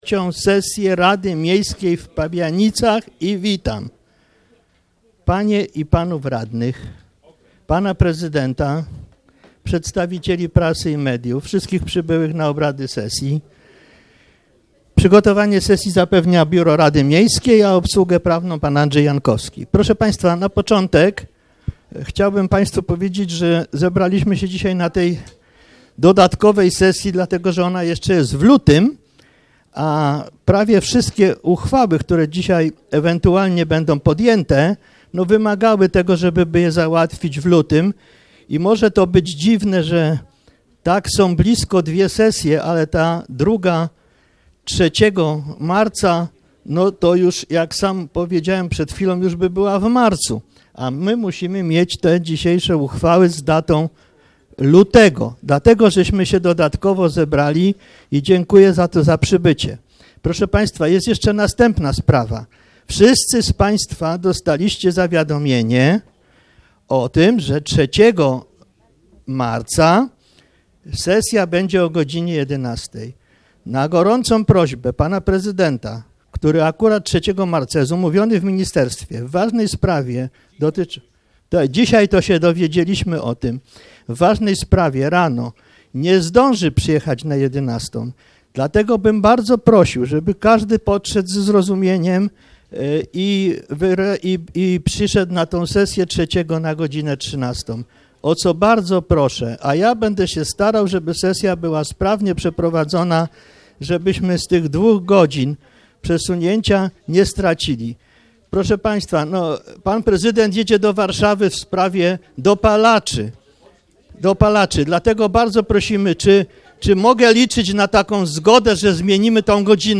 XXIII sesja Rady Miejskiej w Pabianicach - 29 lutego 2016 r. - 2016 rok - Biuletyn Informacji Publicznej Urzędu Miejskiego w Pabianicach